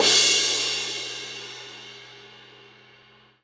Cymbol Shard 19.wav